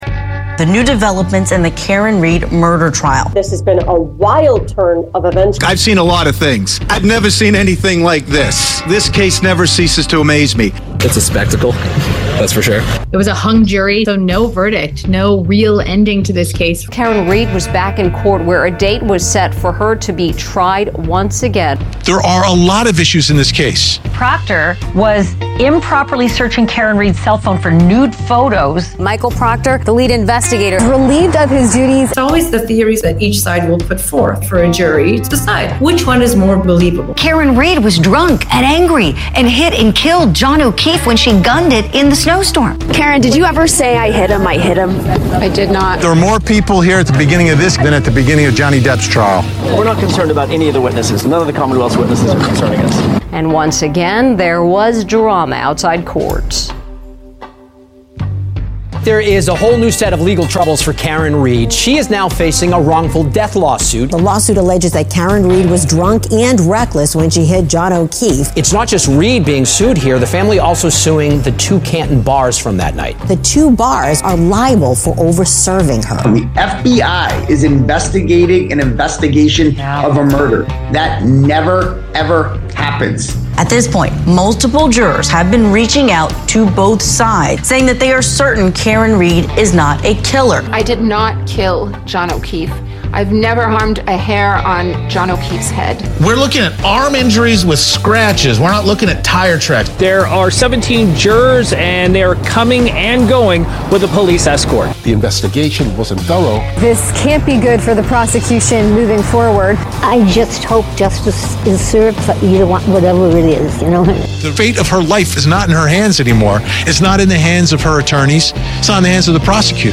Juror Interview, Karen Read Interview, & The Hearing that Caused Judge Cannone to Start Shaking, Cancel the Rest of the Day, and Mention "Grave Concerns"